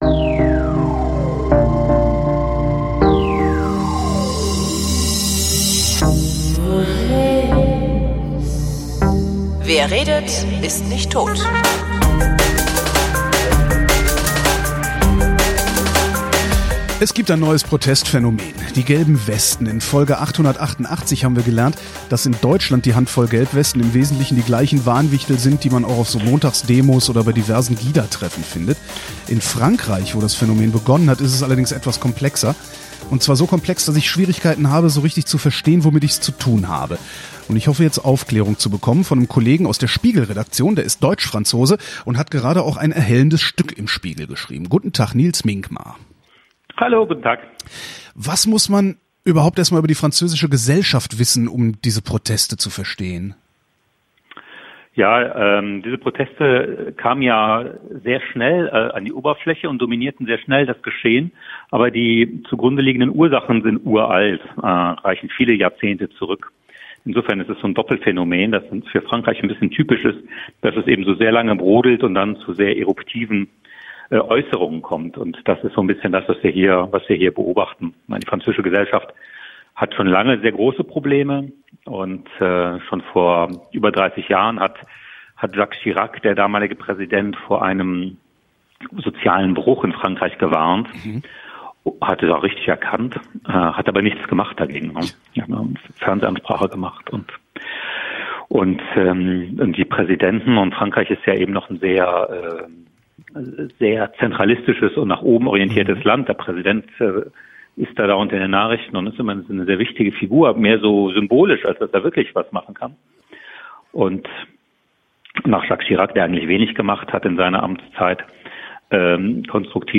wrint: gespräche zum runterladen
Der Spiegel-Journalist Nils Minkmar war so freundlich, mir ein wenig über Frankreich und dessen gelbe Westen zu erzählen.